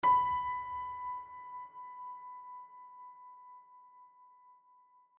piano-sounds-dev
b4.mp3